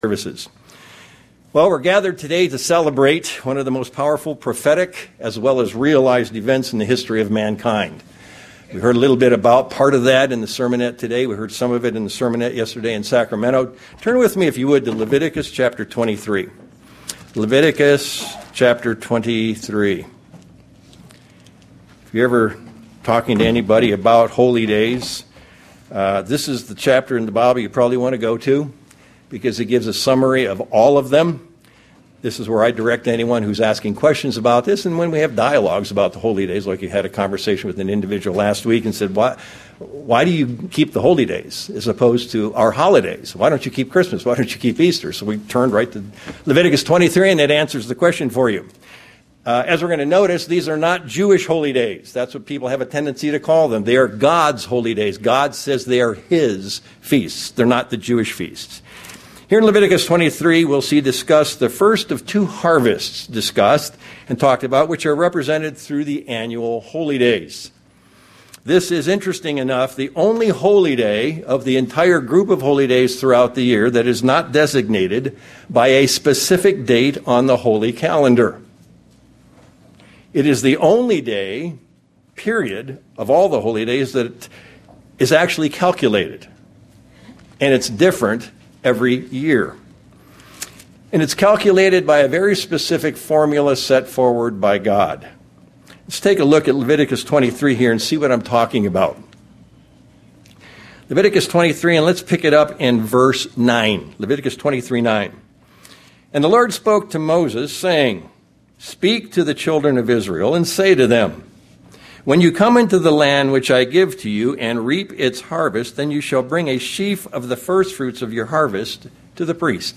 This message was given on the Feast of Pentecost.
View on YouTube UCG Sermon Studying the bible?